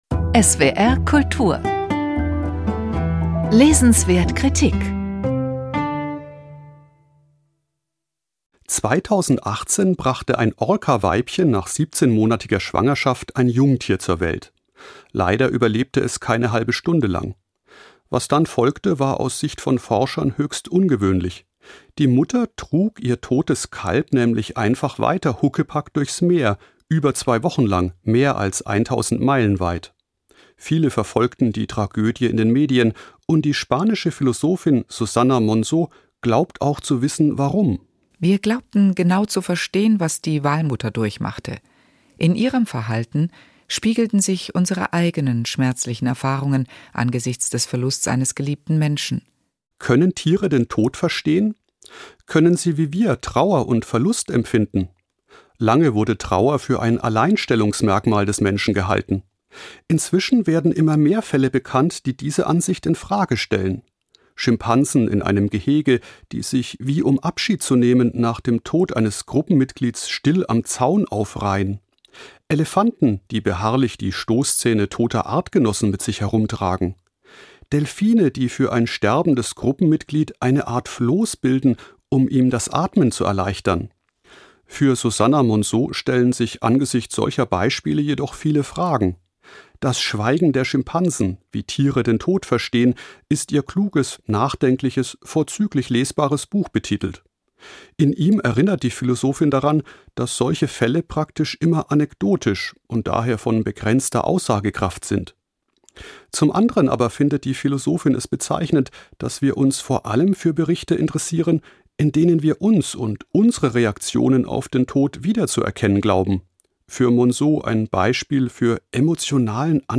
Rezension